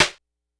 snr_42.wav